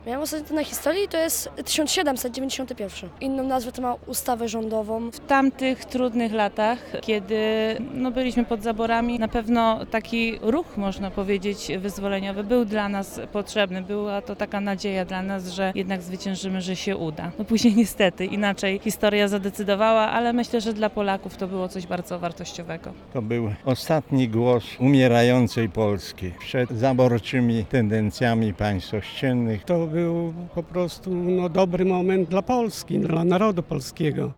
sonda-konstytucja-2.mp3